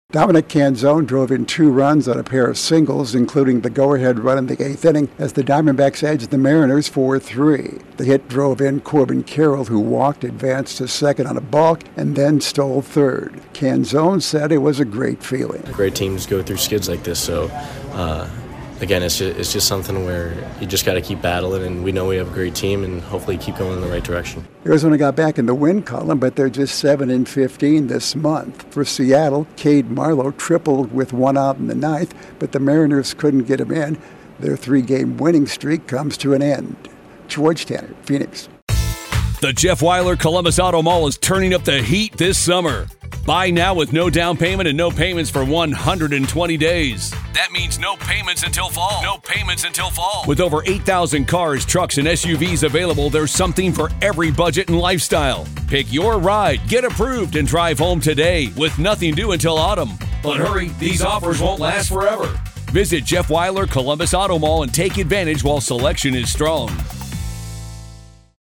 The Diamondbacks bounce back from a three-run deficit to get a badly needed victory. Correspondent